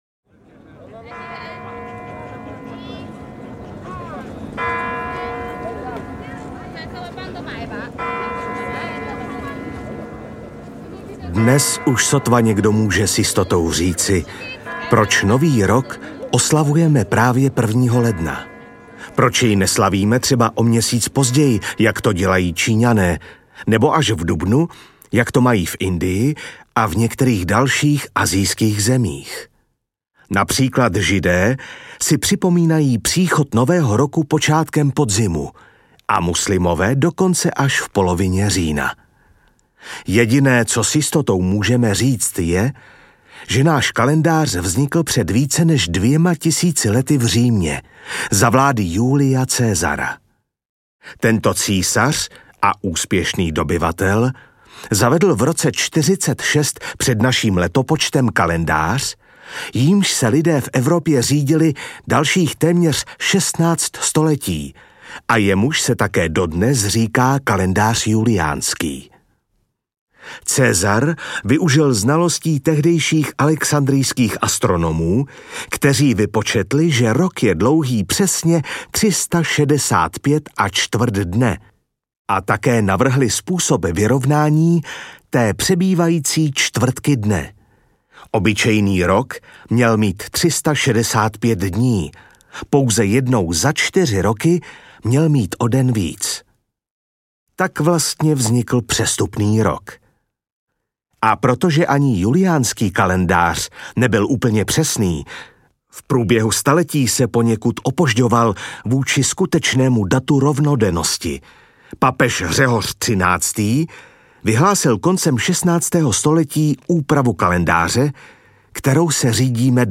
Ukázka z knihy
Vyprávění pro malé i velké posluchače o českých svátcích, zvycích a tradicích v průběhu kalendářního roku.